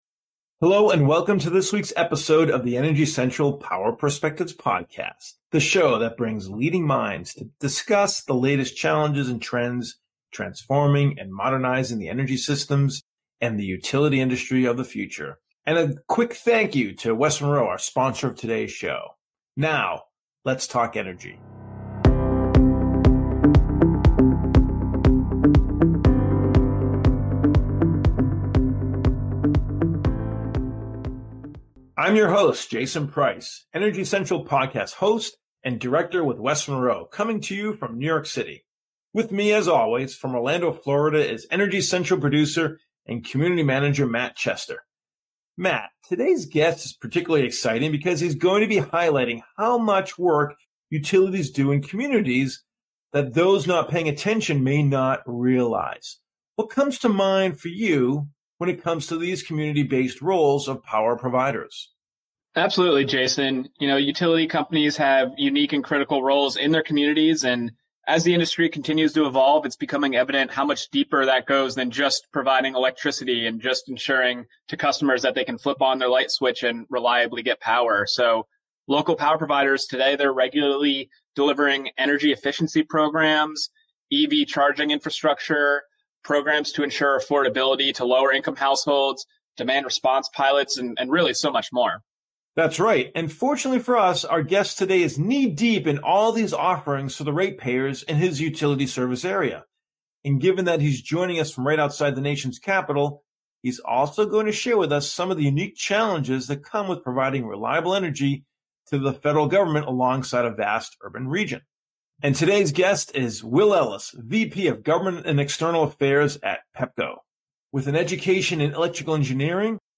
Listen in to hear the enthusiastic conversation.